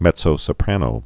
(mĕtsō-sə-prănō, -pränō, mĕdzō-, mĕzō-)